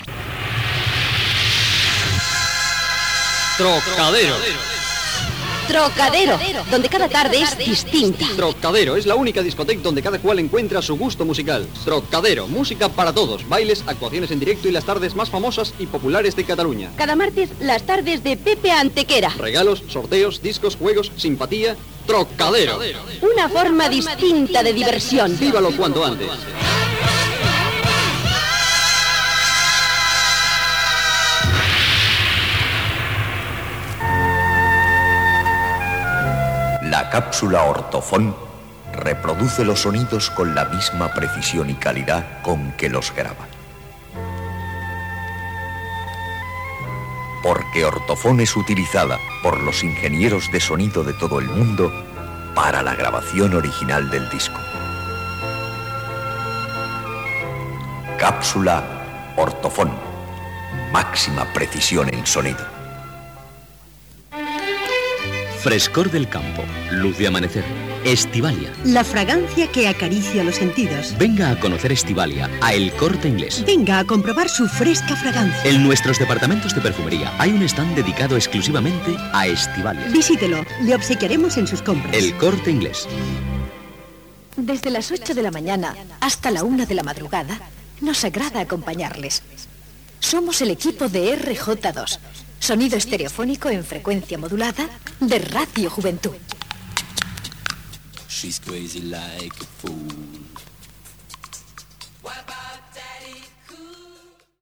Publicitat i identificació de l'emissora
FM